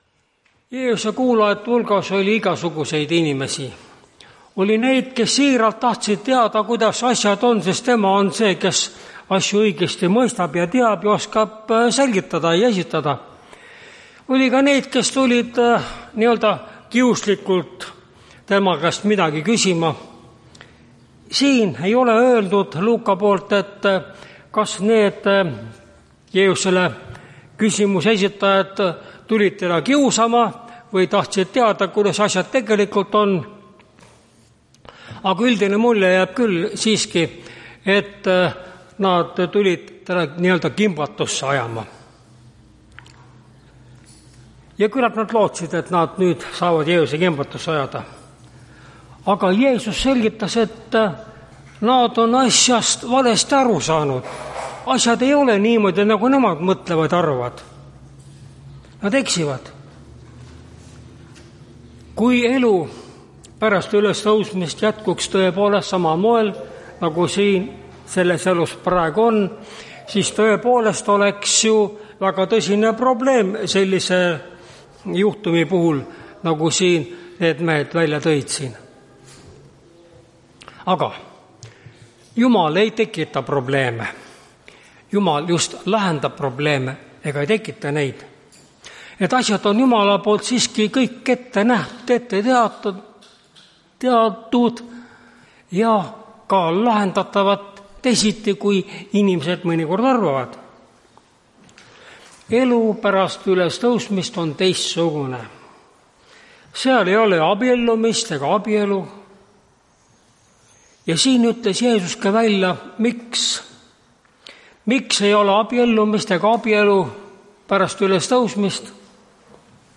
Tartu adventkoguduse 01.06.2024 hommikuse teenistuse jutluse helisalvestis.